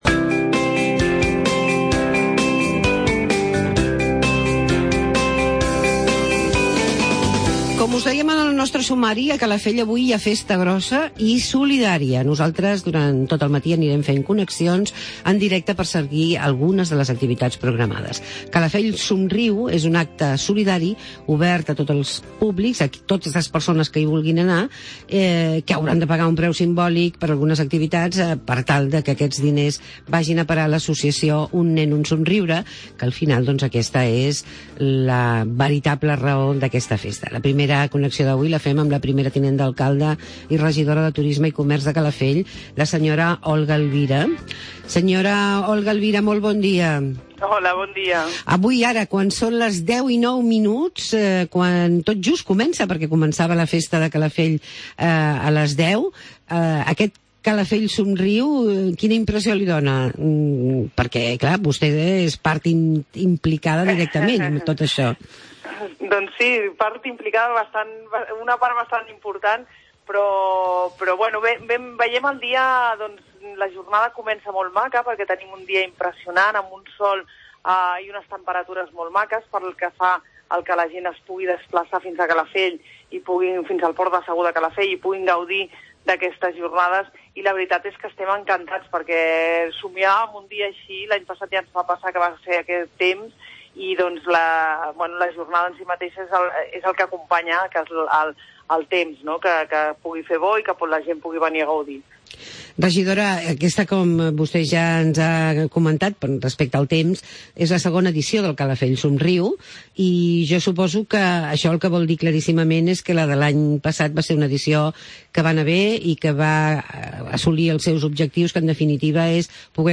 Parlem de "Calafell somriu" amb la regidora de Comerç i Turisme de la localitat, Olga Elvira Cañas